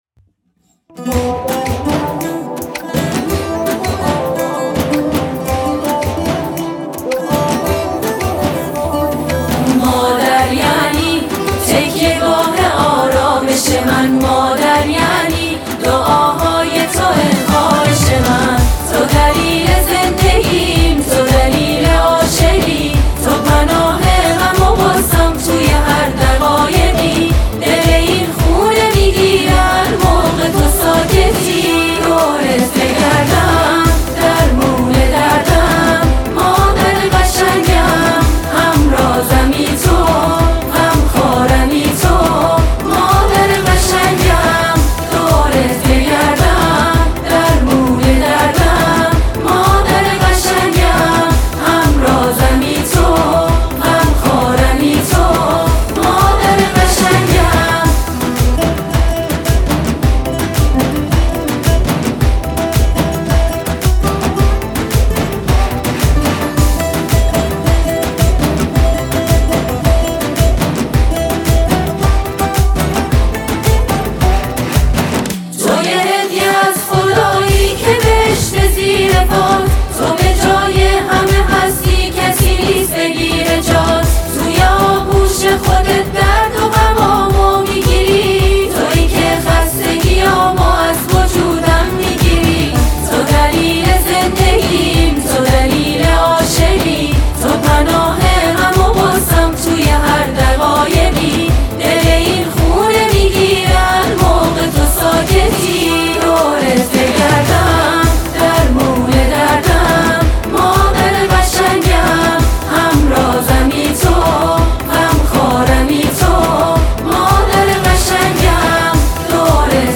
دف نوازی